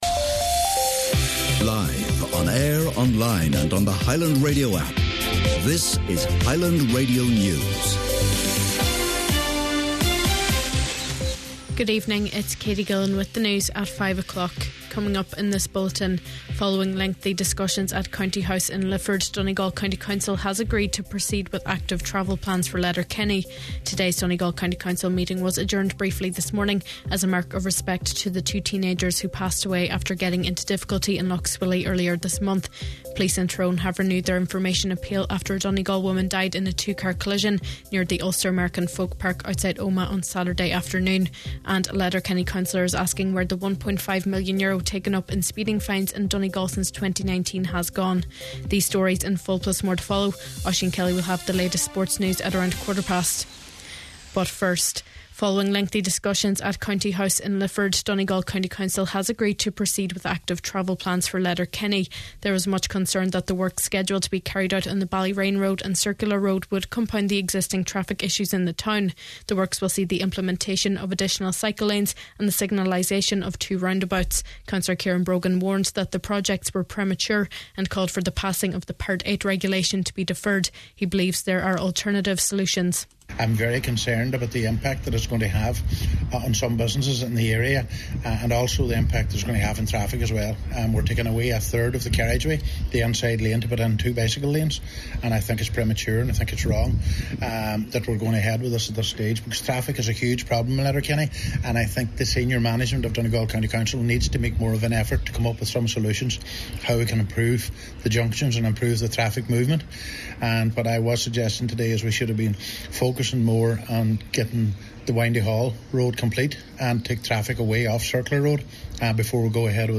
Main Evening News, Sport and Obituaries – Monday May 26th